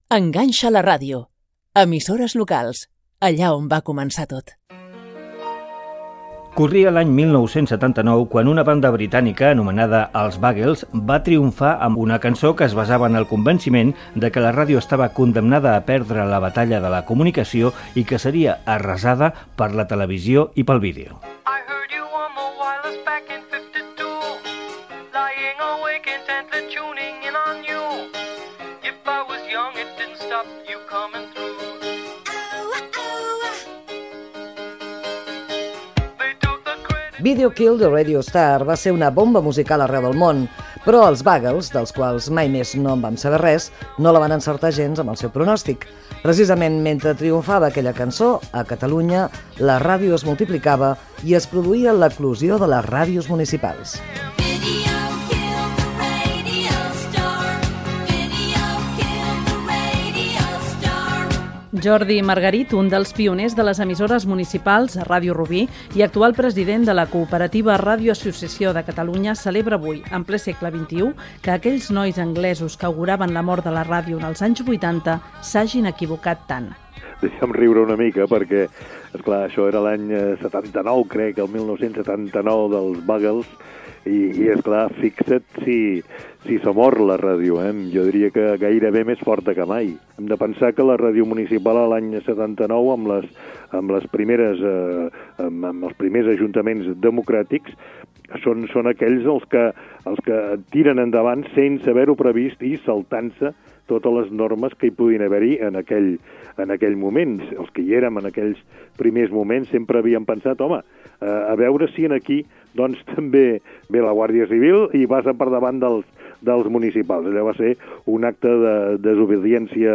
Especial emès amb motiu del Dia Mundial de la Ràdio 2020. Les ràdios municipals catalanes, Ràdio Barcelona, Ràdio Terrassa, Ràdio Girona, etc.
Divulgació